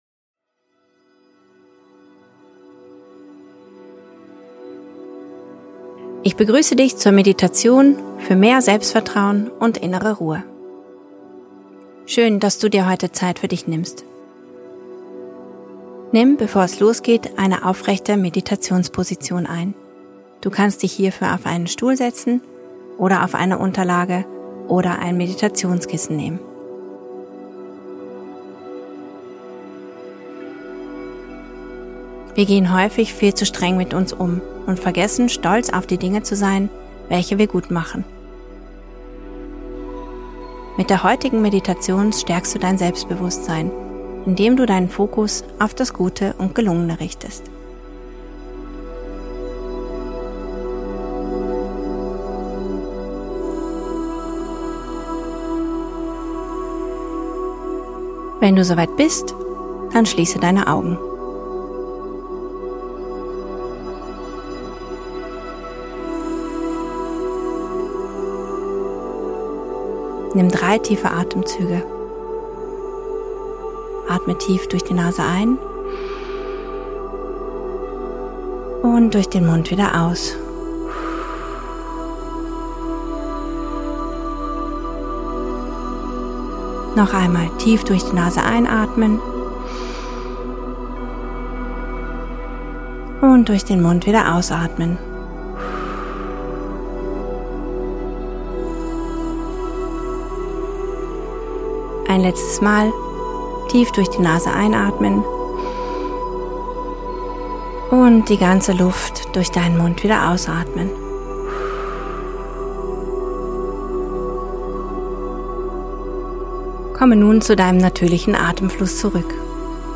Eine geführte Meditation zur Entwicklung von Selbstvertrauen. Mit dieser Meditation kann man in sich gehen und sein eigenes Selbst entdecken.